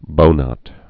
(bōnŏt)